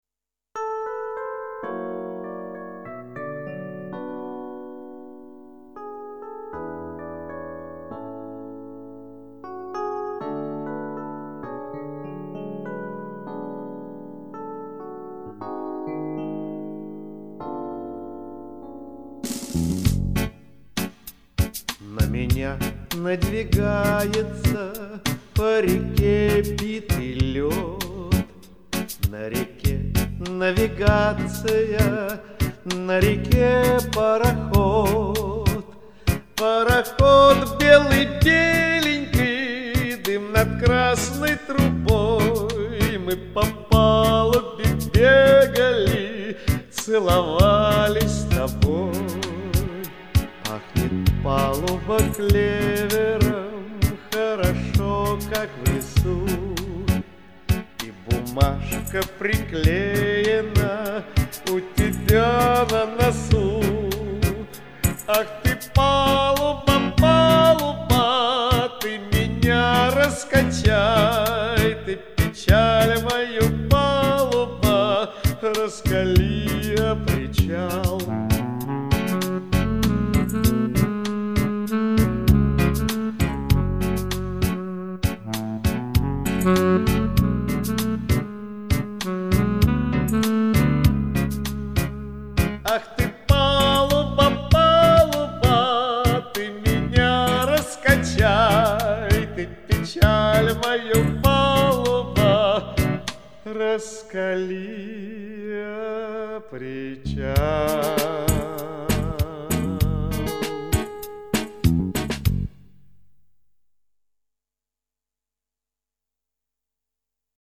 А вот из фильма.